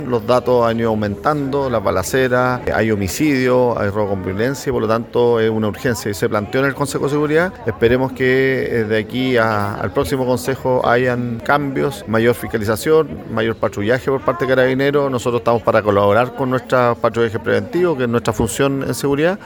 Por otro lado, desde la Municipalidad de Concepción, el alcalde Héctor Muñoz, se refirió al aumento de delitos, y espera que las cifras disminuyan para el próximo mes.